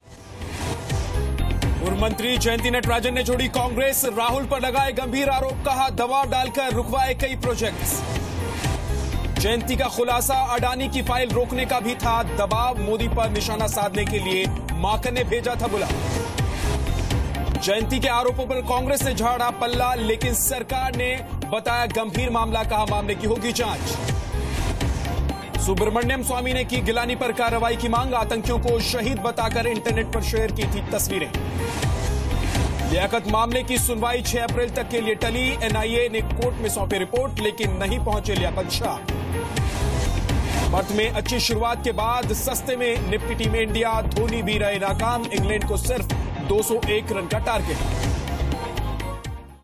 Headlines of the day